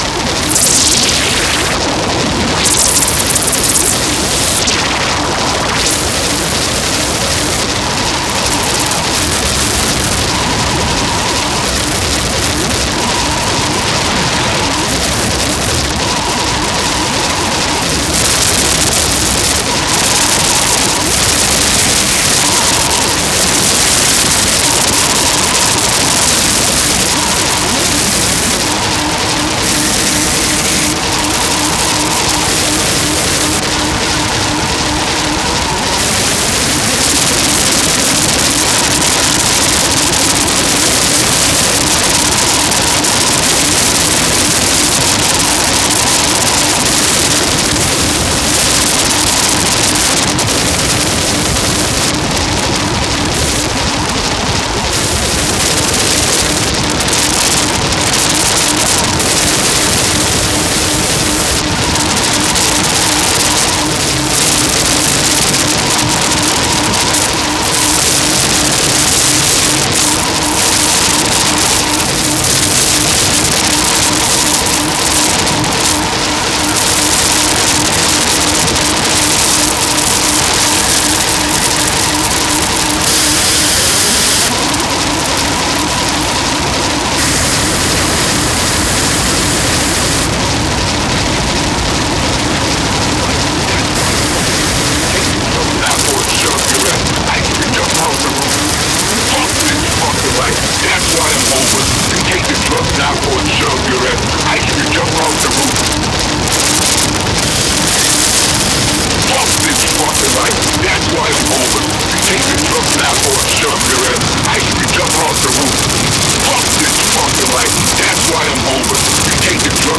Industrial bleakness and cold rhythms
• Genre: Industrial